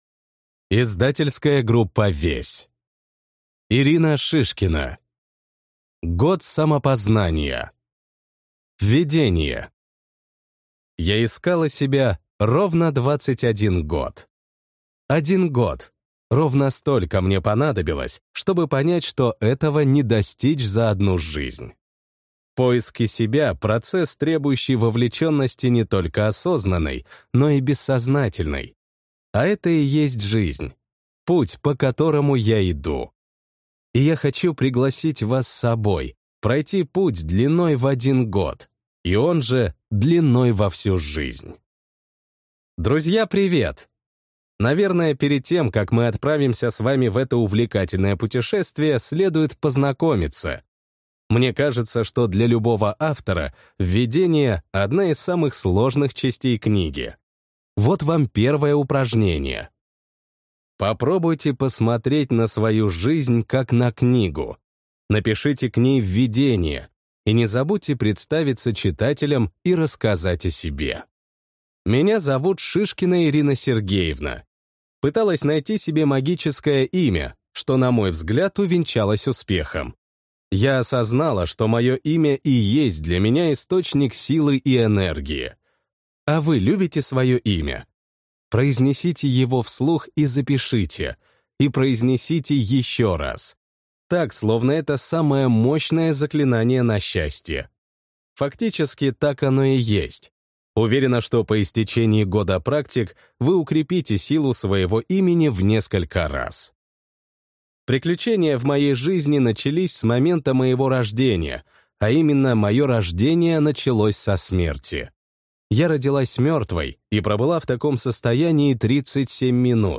Аудиокнига Год самопознания. Ритуалы, практики и медитации, меняющие жизнь | Библиотека аудиокниг